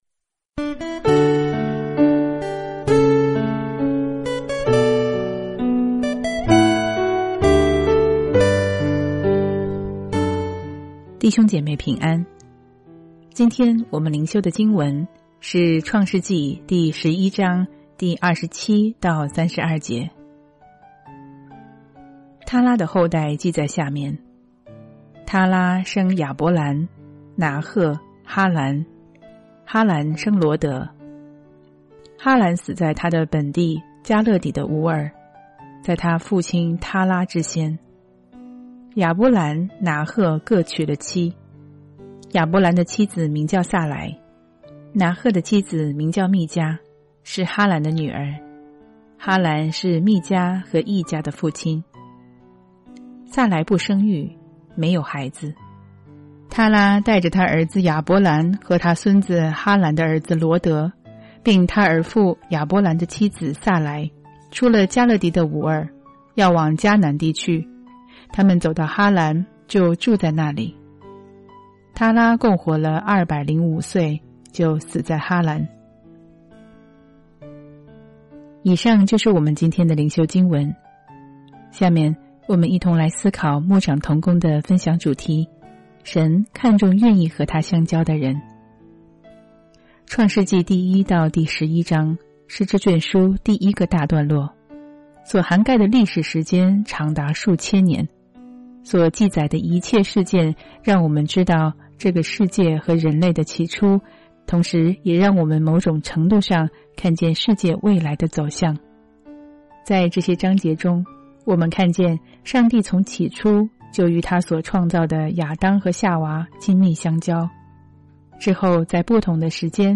每天閱讀一段經文，聆聽牧者的靈修分享，您自己也思考和默想，神藉著今天的經文對我說什麼，並且用禱告來回應當天的經文和信息。